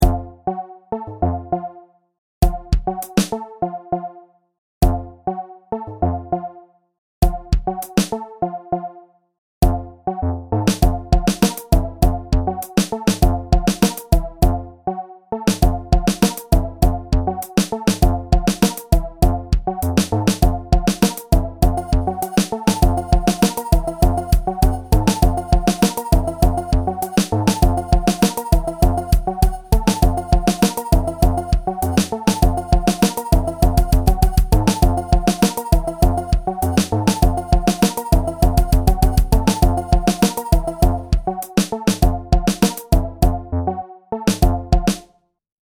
Bucle de Break Beat
Música electrónica pieza melodía repetitivo sintetizador